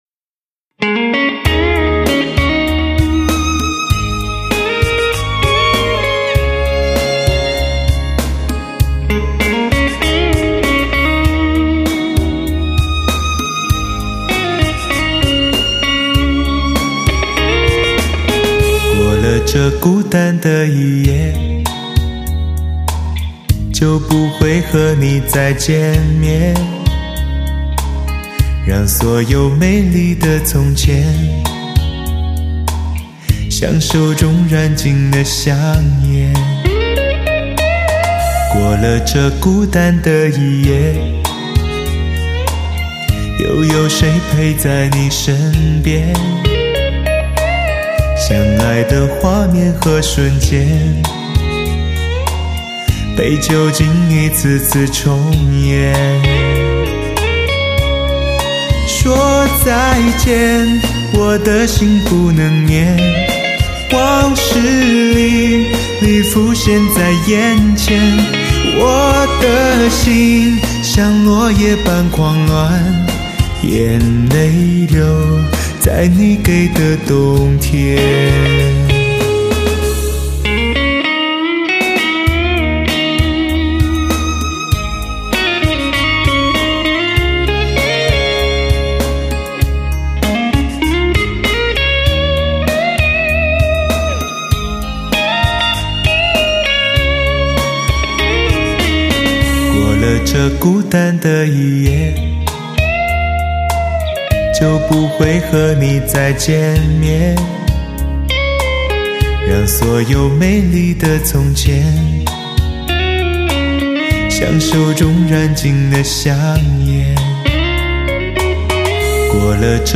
开创革命性的 STS+SRS全方位环绕HI-FIAUTO SOUND 专业天碟，
专有STS Magix 母带制作，STS magix virtual live高临场感CD。